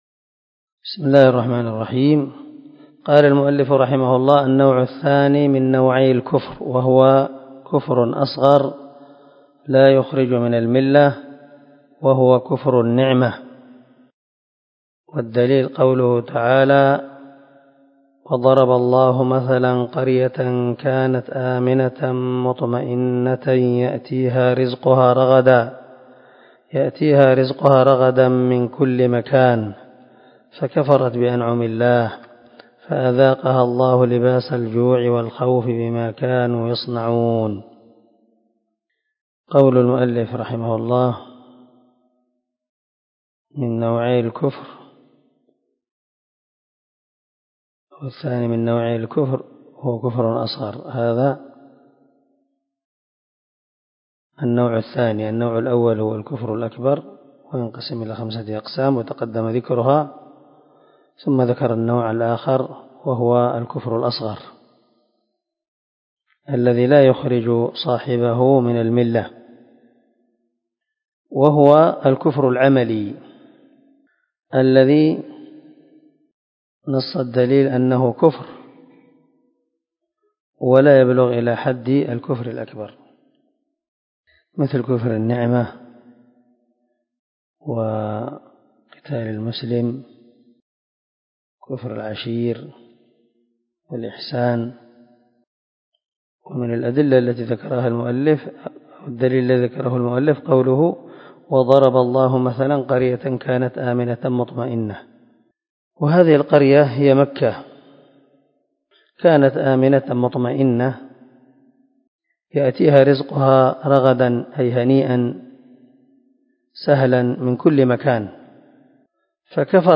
🔊الدرس 36 النوع الثاني من نوعي الكفر الكفر الأصغر